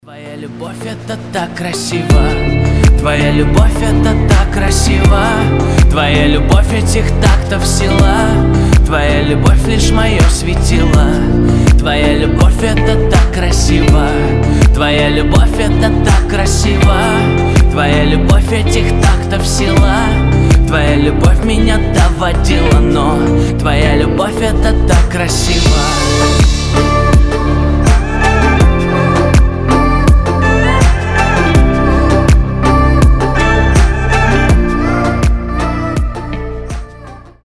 Романтические рингтоны
Поп